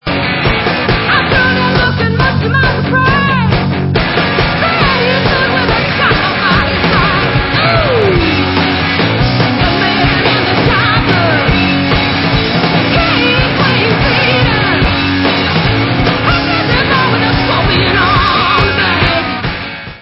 rocking-soul side-project
sledovat novinky v kategorii Rock